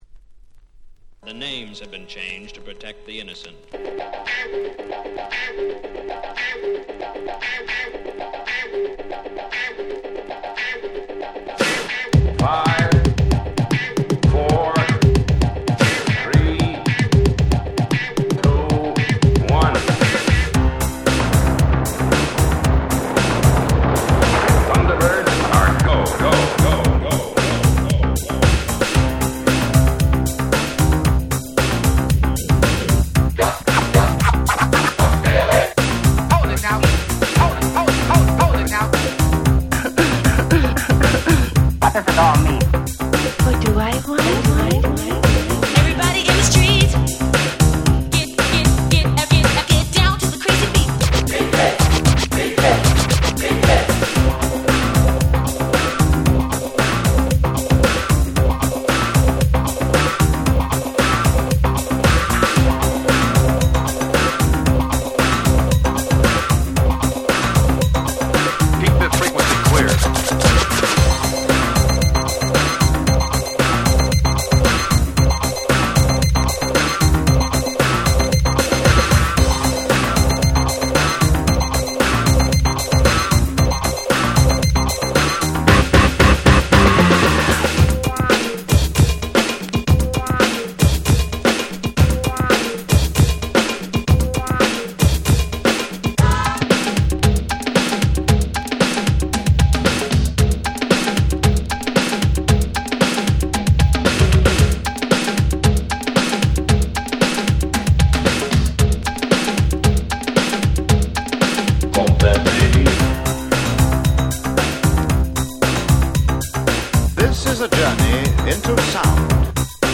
88' Break Beats Classics !!